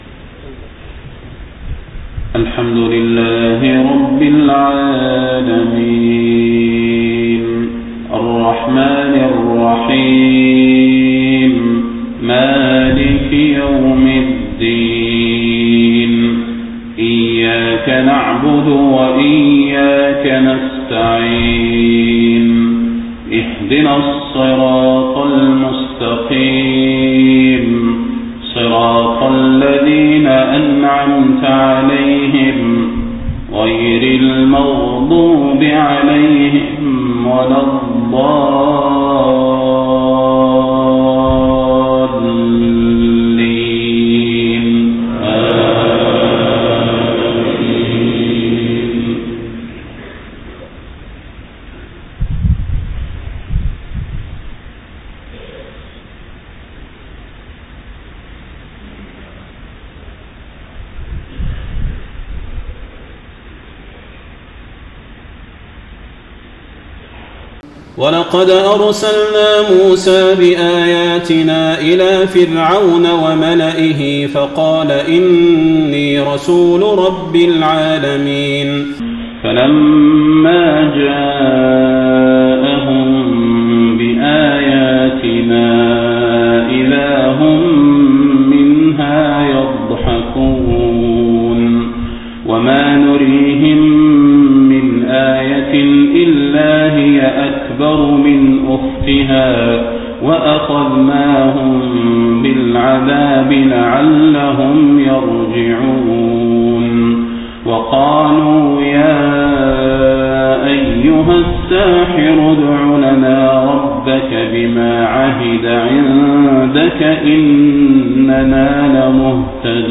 صلاة الفجر 10 ربيع الأول 1431هـ من سورة الزخرف 46-73 > 1431 🕌 > الفروض - تلاوات الحرمين